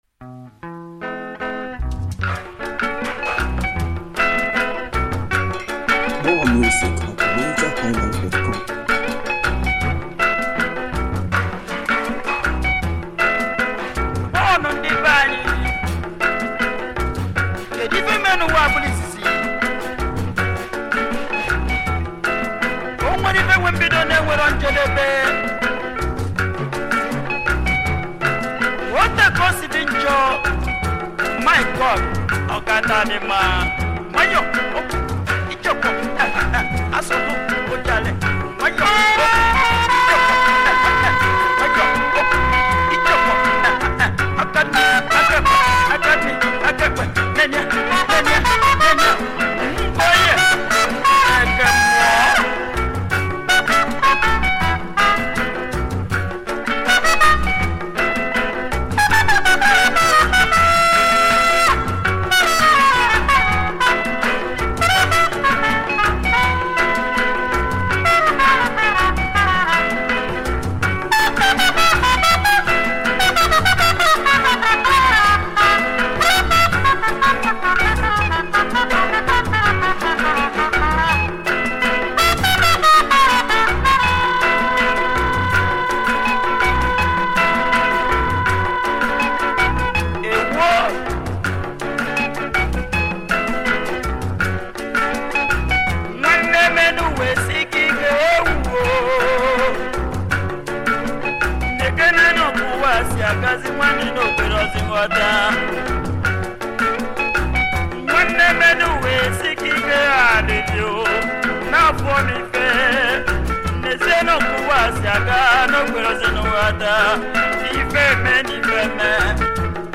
Home » Highlife